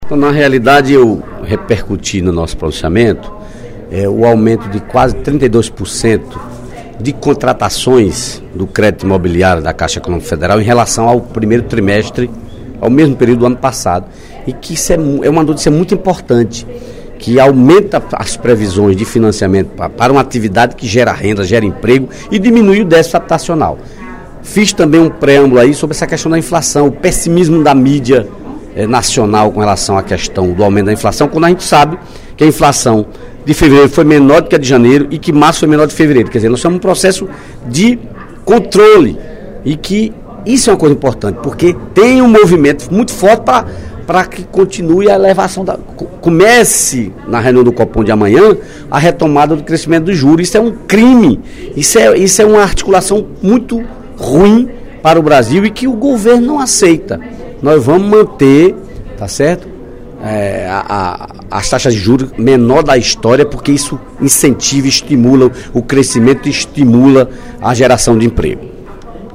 O deputado Dedé Teixeira (PT) apontou, nesta terça-feira (16/04), durante o primeiro expediente da sessão plenária, o crescimento das operações de crédito imobiliário da Caixa Econômica Federal.